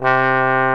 TROMBONE 206.wav